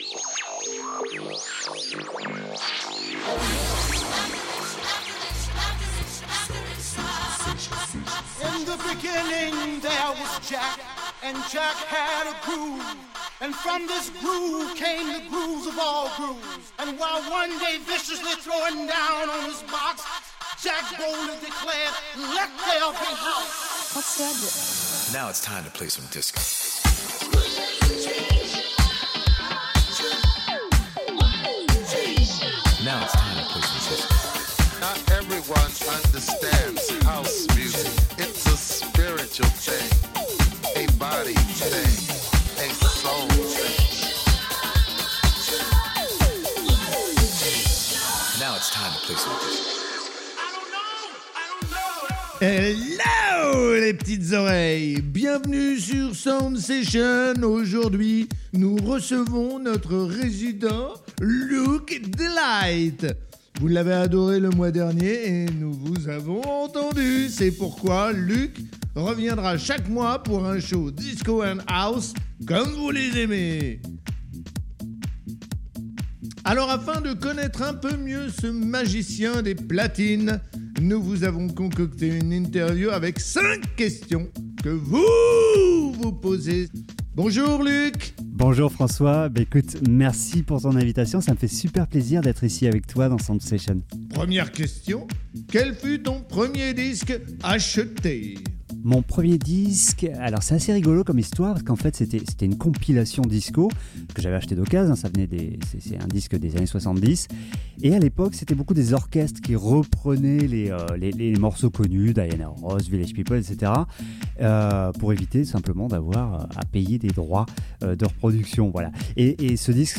Autumn, Atmosphere Sparrows, Starlings roosting in thicket, lively